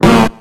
Cries
SNORLAX.ogg